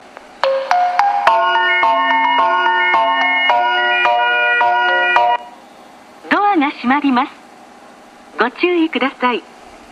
３番線JM：武蔵野線